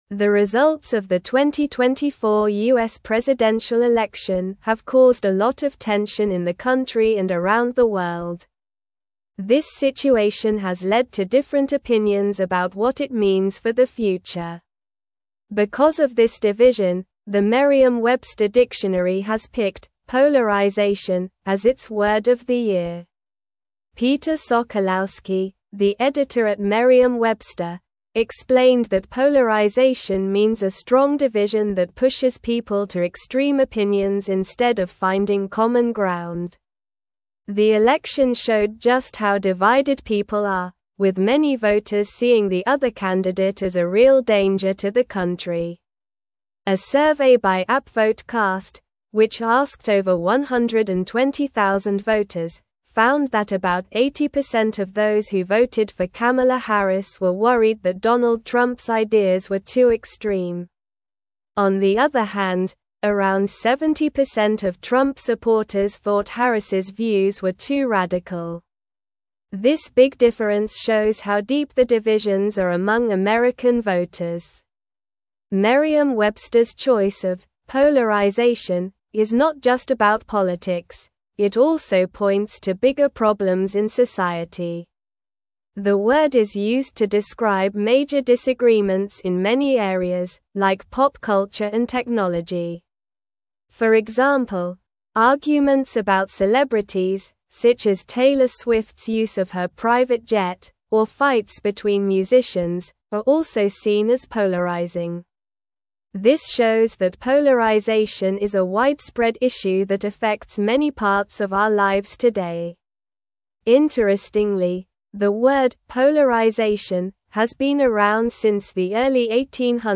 Slow
English-Newsroom-Upper-Intermediate-SLOW-Reading-Polarization-Named-Merriam-Websters-Word-of-the-Year.mp3